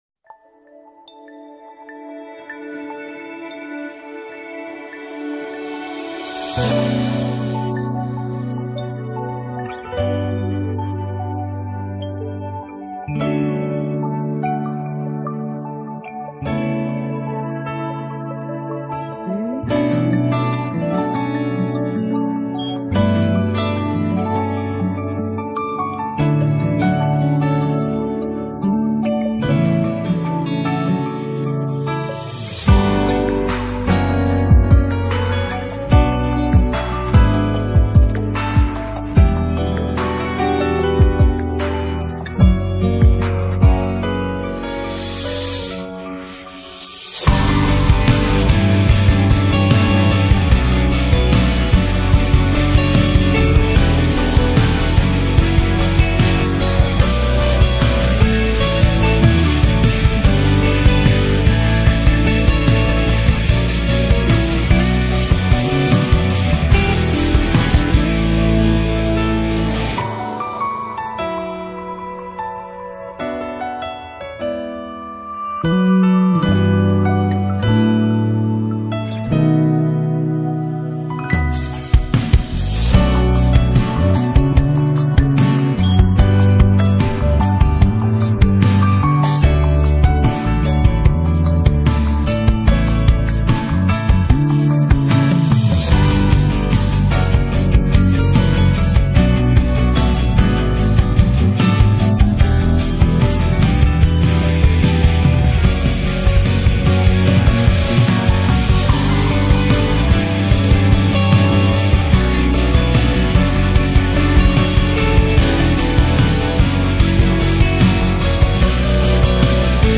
卡拉OK 伴奏版